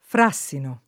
fr#SSino] s. m. — es. con acc. scr.: una fogliolina di fràssino [